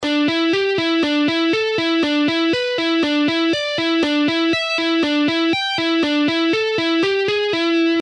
Same lick in half Speed :
Half-Speed-Pentatonic-Scale-Guitar-Licks-1-.mp3